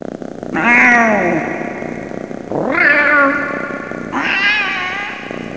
Cat
cat.mp3